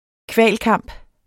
Udtale [ ˈkvaˀl- ]